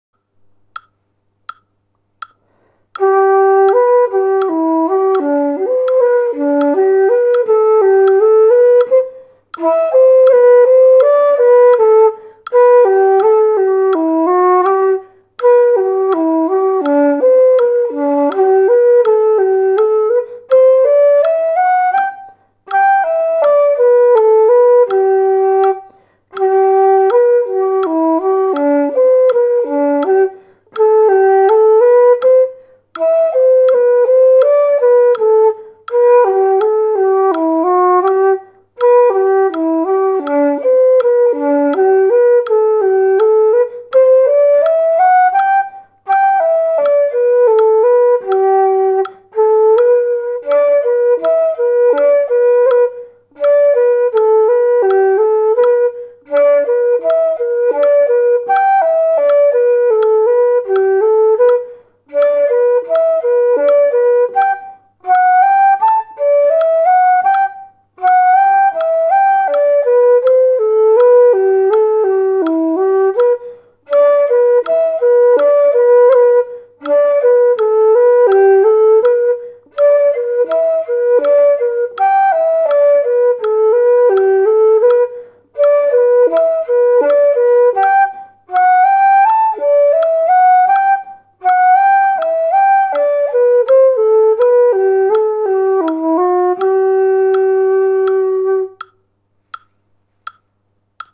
music_in_the_glen_training_track.mp3